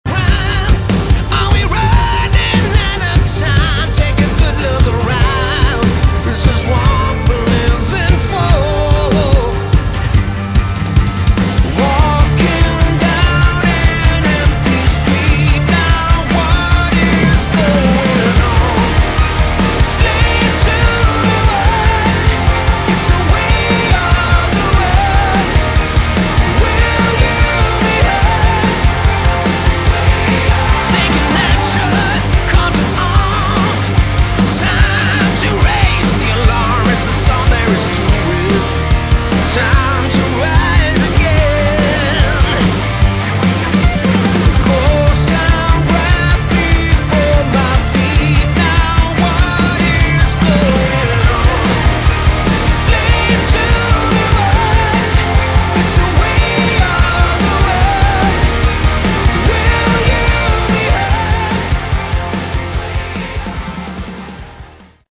Category: Melodic Rock
bass, vocals
drums
keyboards
guitars